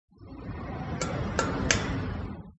描述：este audio hace parte del foley de“the Elephant's dream”
Tag: 步行 金属 步骤